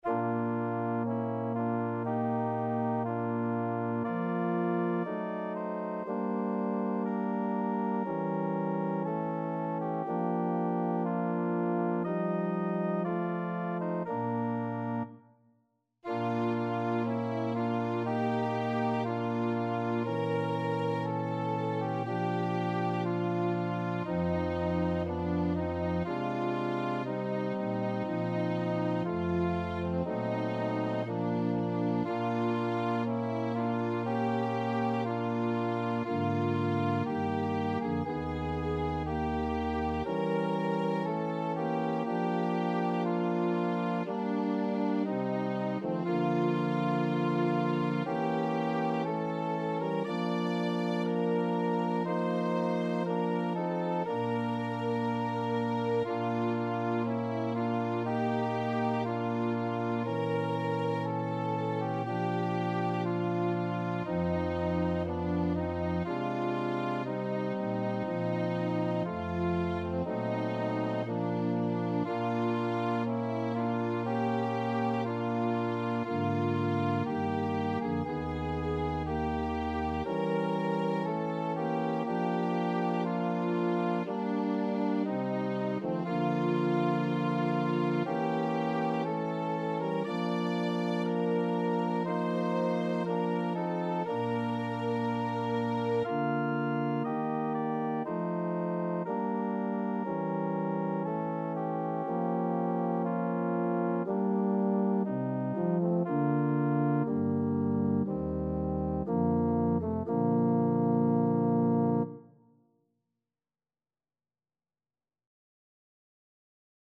1v Voicing: Unison Genre: Sacred, Art song
Language: Portuguese Instruments: Organ